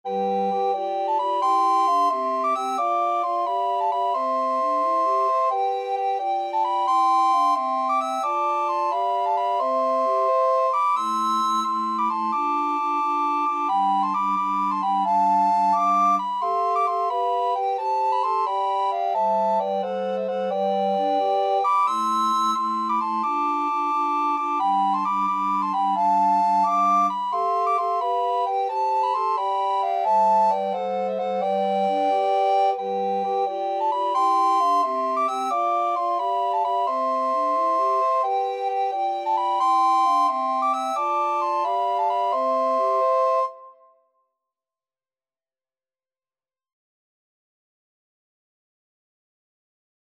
Soprano RecorderAlto RecorderTenor RecorderBass Recorder
6/8 (View more 6/8 Music)